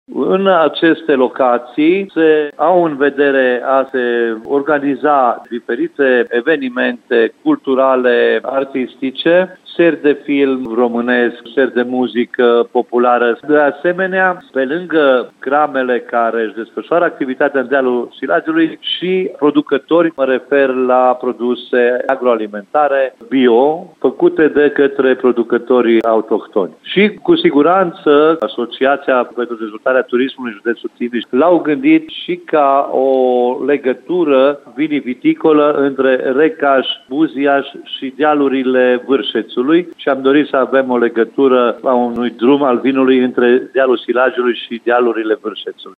Vor fi organizate evenimente pe tot parcursul anului Capitalei Europene a Culturii și pentru ca turiștii, care ajung în Timișoara să poată descoperi frumusețile dealului Silagiului, cu cele cinci crame de aici, spune primarul orașului Buziaș, Sorin Munteanu.